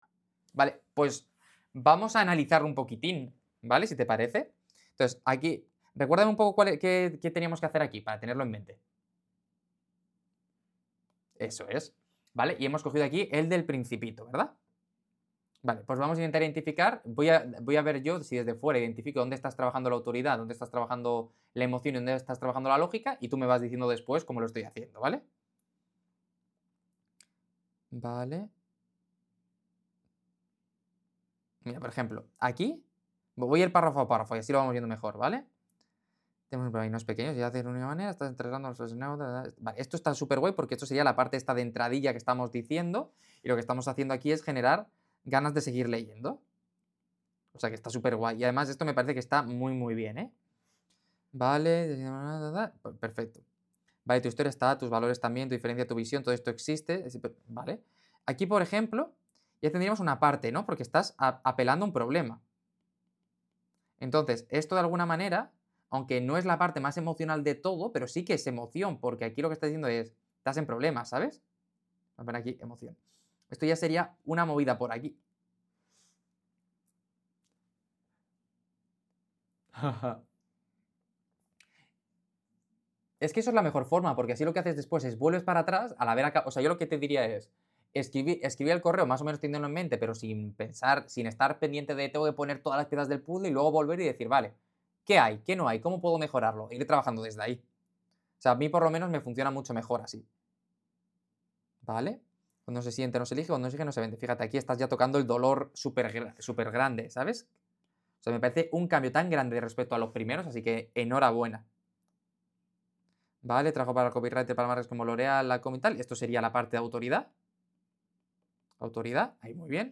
Copywriter con más de 10 años de experiencia analiza un email de un alumno en directo.